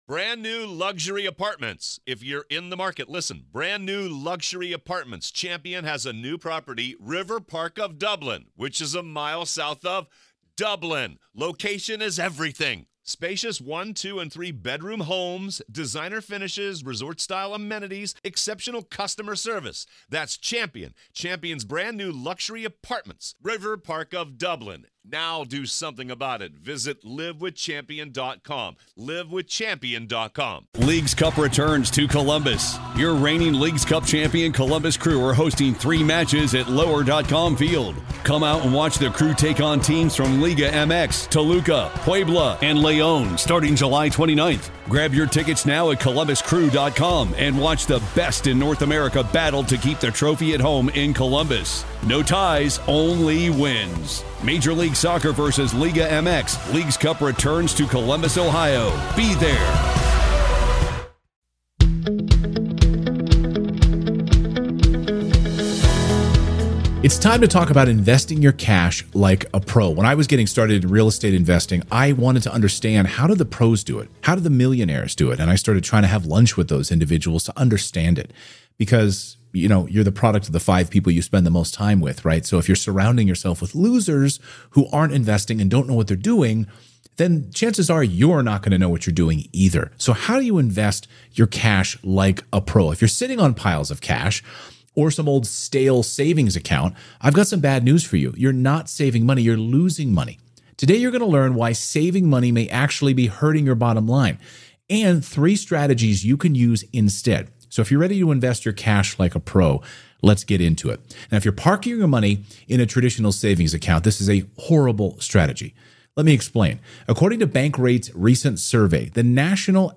Today’s first caller owns a couple of rental properties alongside her husband. As they reach retirement age, what’s the best investment type? Should they open a self-directed IRA or buy properties in other ways?